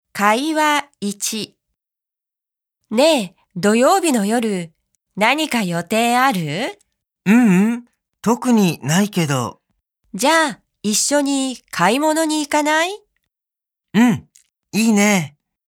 ．有聲MP3：由專業日籍錄音老師所錄製的朗讀音源，收錄單字表、長會話、短會話、綜合練習等內容，提供教師配合課程進度在課堂上使用，學生也能在課後練習日語發音和語調。